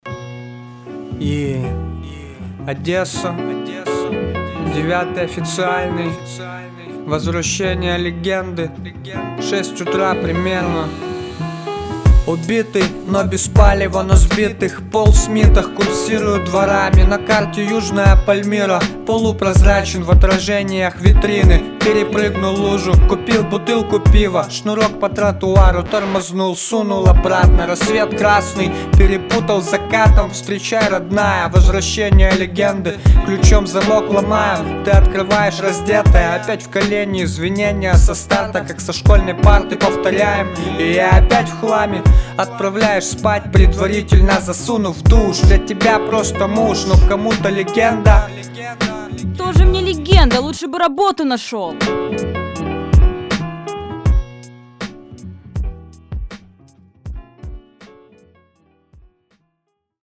Яркий андерграунд-проект из города-героя Одессы.
Очень яркий и талантливый хип-хоп.